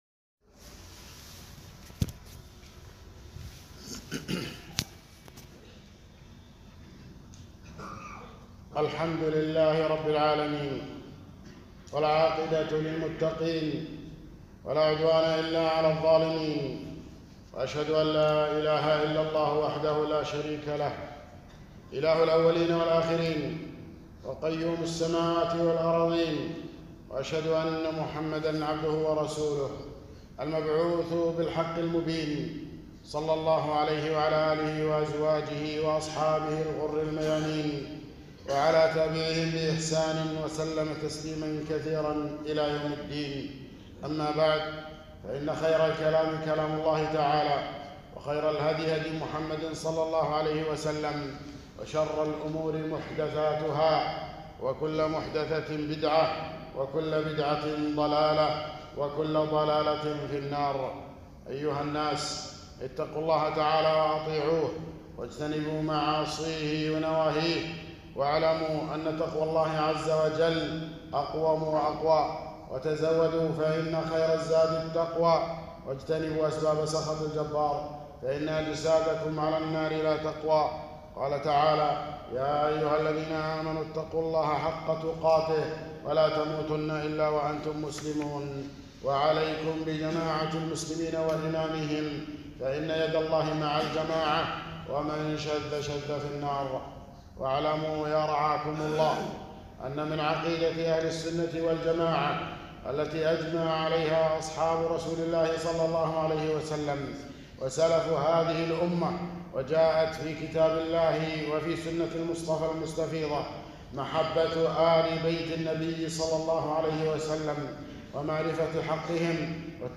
خطبة - آل بيت النبي صلى الله عليه وسلم